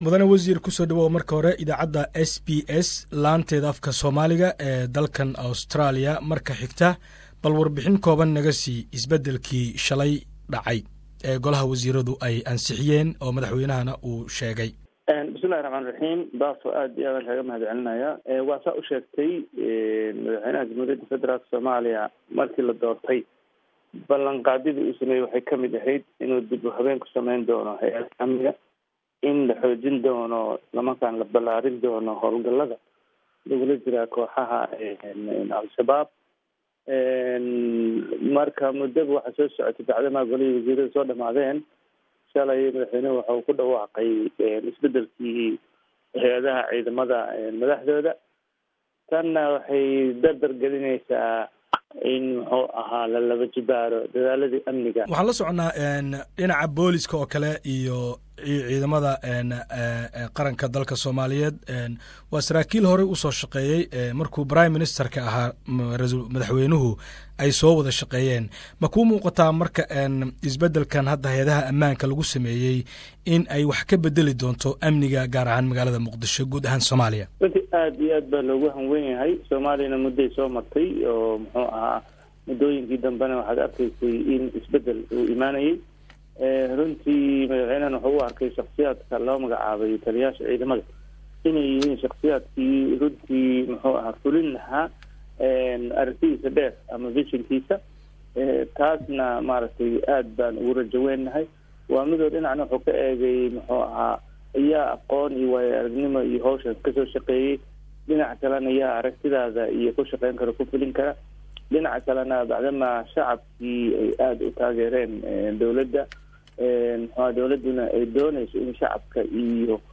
Wareysi Gaar aan layeelanay wasiirka warfaafinta Dowlada Federalka Somaliya Eng. Cabdiraxmaan yariisow ayaa waxa uu sheegay in dalka isbedel dhanka amaanka lagu sameeyey oo dawlada ay ka go'antahay in ay dalka nabadeyso guud ahaan gaar ahaan Caasimada Somalia Muqdisho